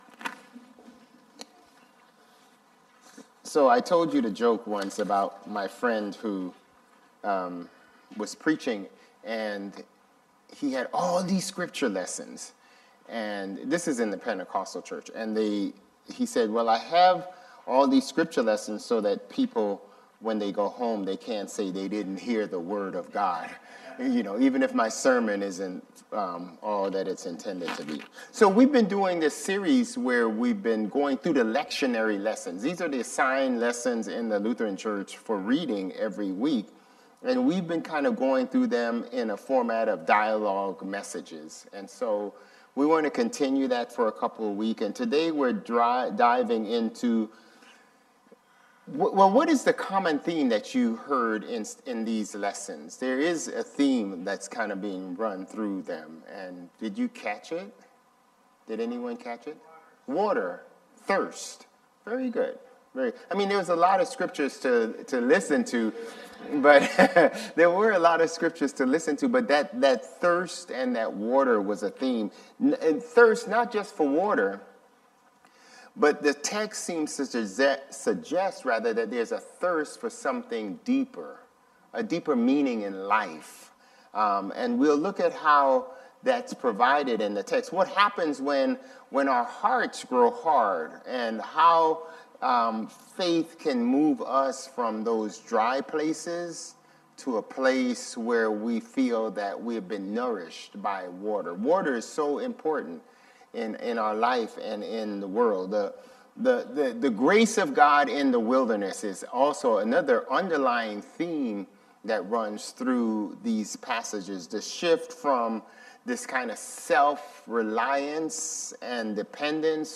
March 8 Worship